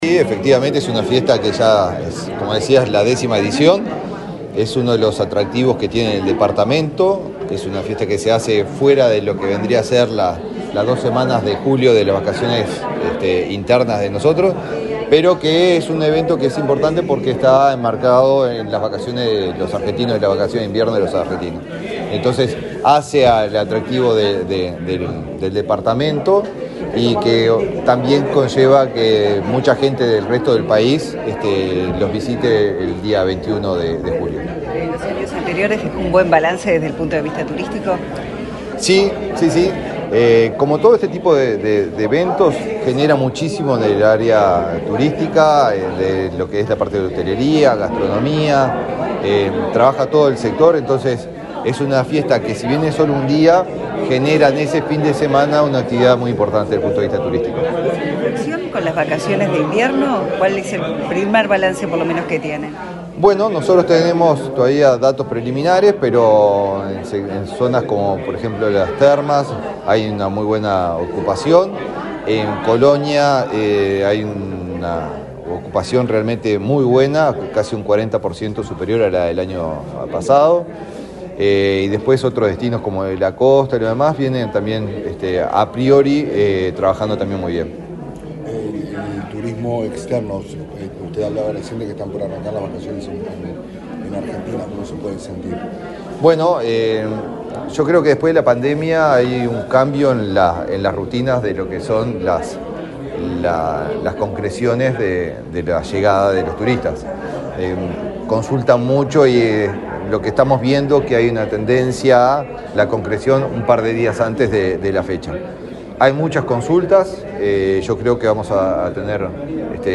Declaraciones del ministro de Turismo, Eduardo Sanguinetti
El ministro de Turismo, Eduardo Sanguinetti, dialogó con la prensa, luego de participar, este miércoles 10 en Montevideo, en el lanzamiento de la 10.ª